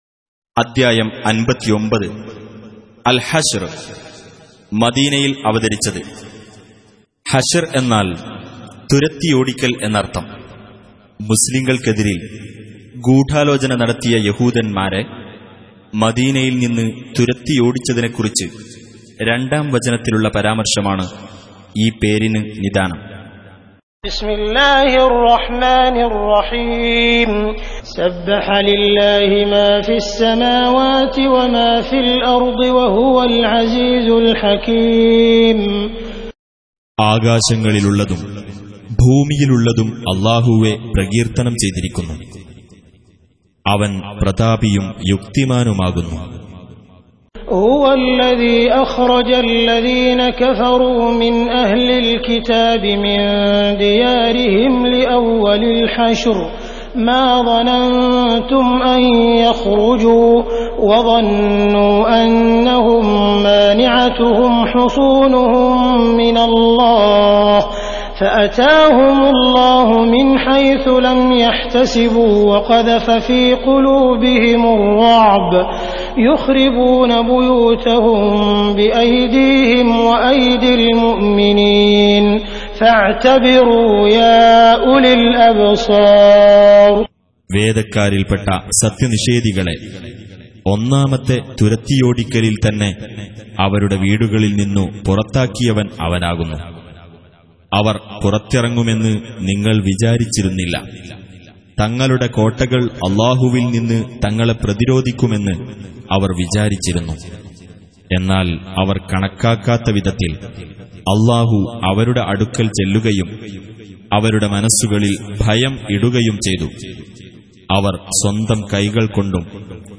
Surah Repeating تكرار السورة Download Surah حمّل السورة Reciting Mutarjamah Translation Audio for 59. Surah Al-Hashr سورة الحشر N.B *Surah Includes Al-Basmalah Reciters Sequents تتابع التلاوات Reciters Repeats تكرار التلاوات